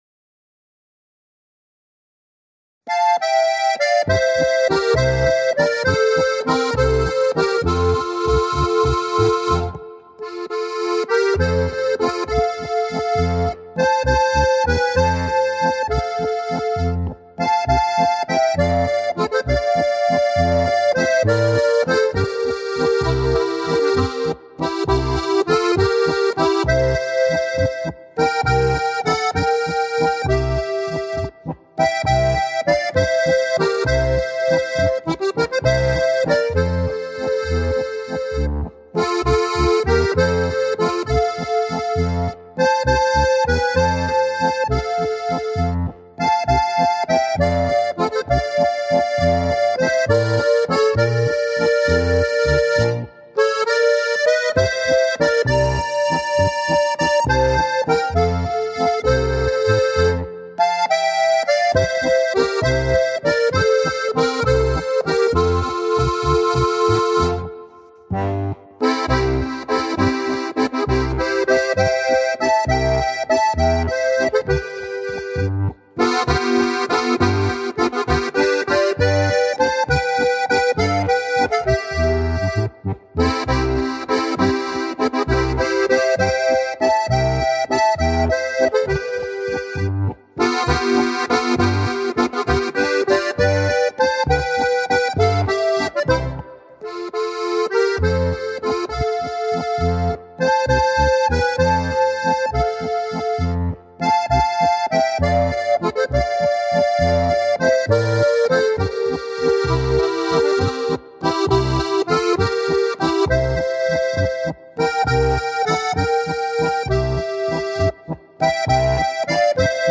Ein wunderschöner Walzer!
• 4-reihige Harmonika